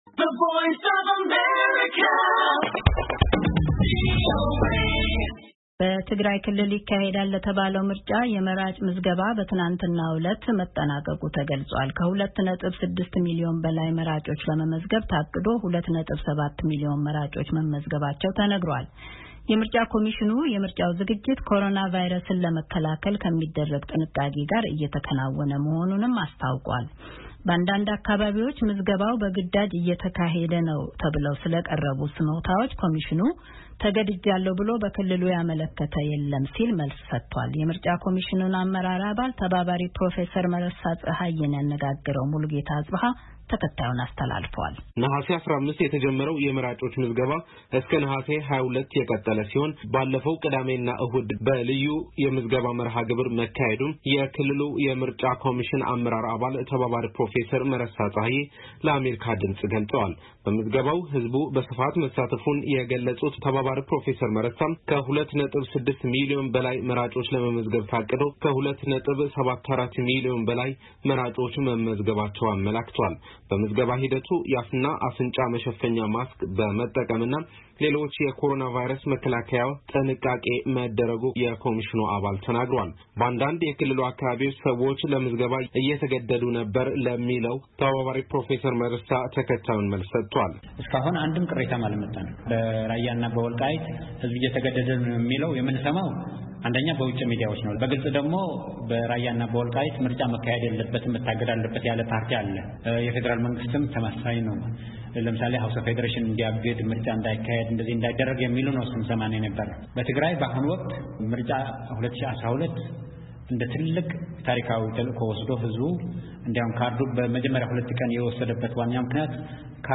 የኮሚሽኑን አመራር አባል ተባባሪ ፕሮፌሰር መረሳ ፀሃዬን አነጋግረናል።